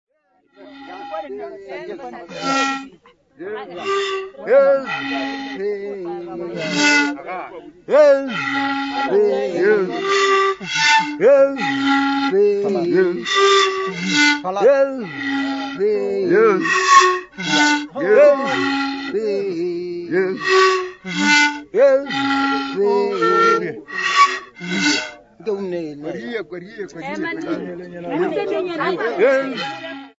Mapulana men and women (Madika initiated young men and women)
Folk music--Africa
Field recordings
Africa South Africa Bushbuck Ridge f-sa
sound recording-musical
All the people moving anti-clockwise around the three drums.
Once the third player came in, in between the other two, the music came alive and all joined in with a will.
Initiation song and pipe dance, with 4 metal pipes and 3 drums Moropa o muyana and Moropa o mkula, 1 bushbuck horn and 1 kudu horn
96000Hz 24Bit Stereo